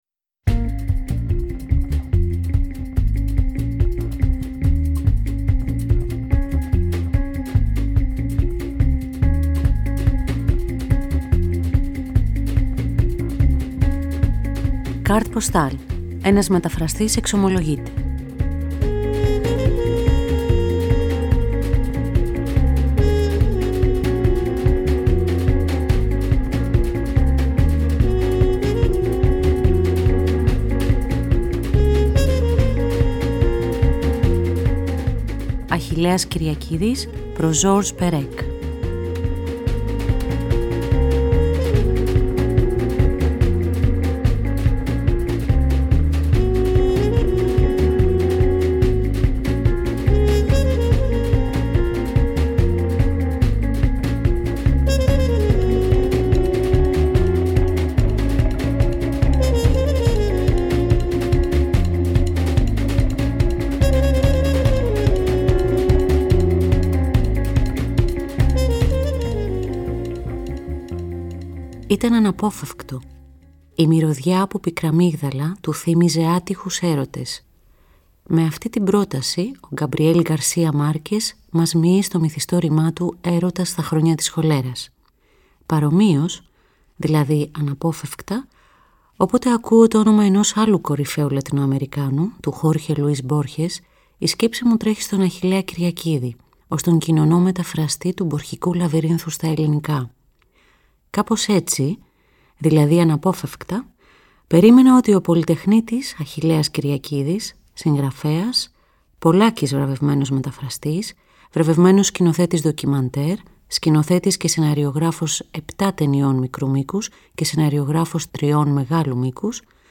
Παράλληλα, διαβάζονται αποσπάσματα από το βιβλίο.